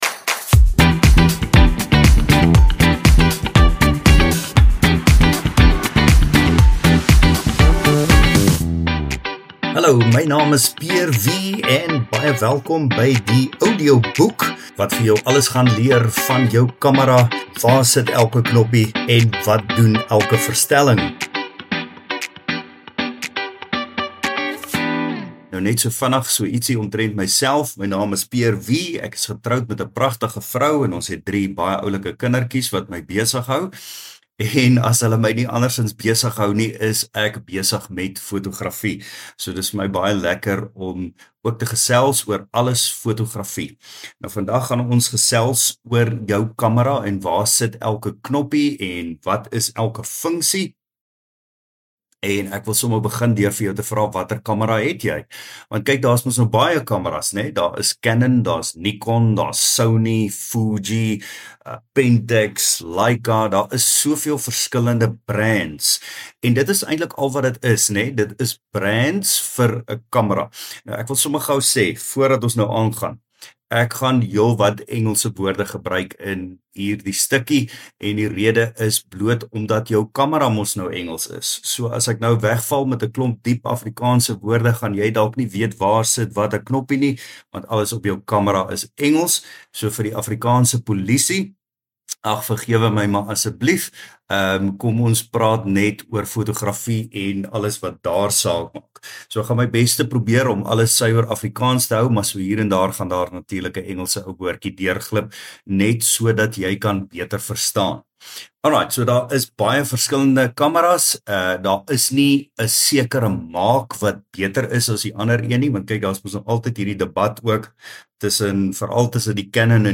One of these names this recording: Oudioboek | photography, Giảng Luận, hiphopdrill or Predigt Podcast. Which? Oudioboek | photography